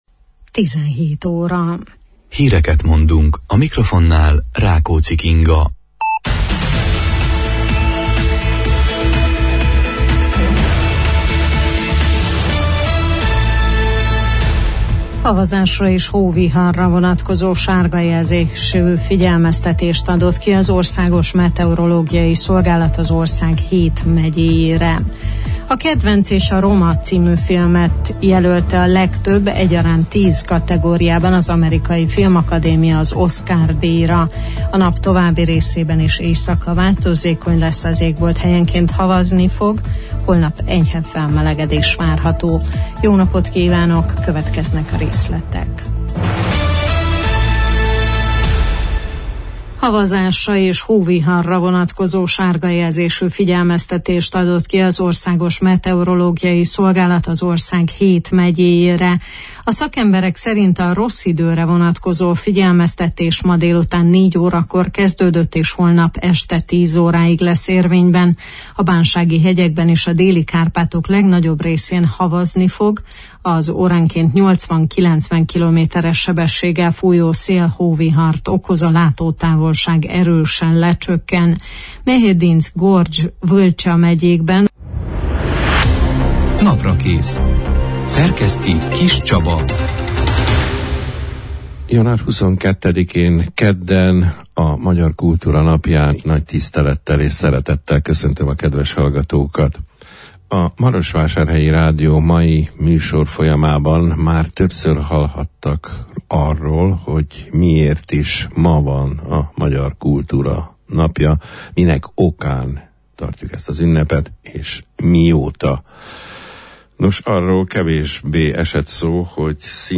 A magyar kultúra napjának jelentőségéről, a hozzá kapcsolódó eseményekről beszélgettünk